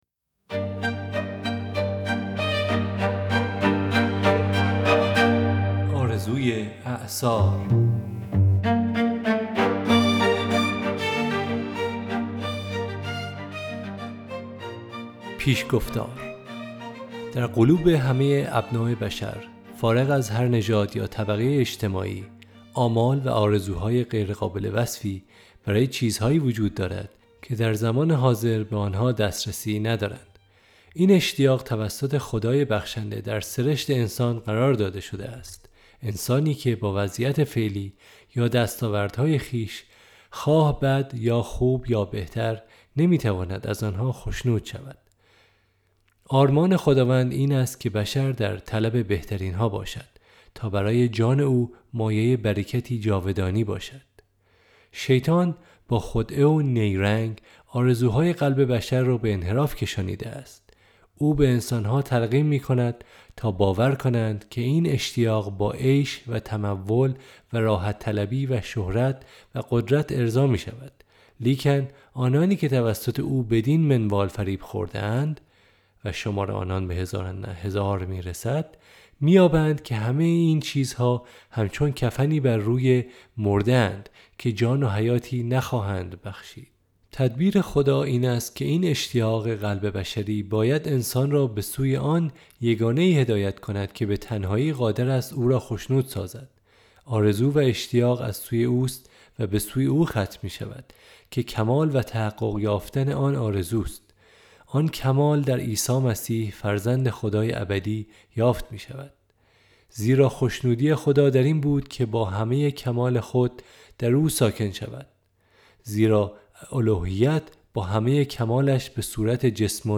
کتاب صوتی : "آرزوی اعصار"
کتاب صوتی آرزوی اعصار نوشته خانم الن جی وایت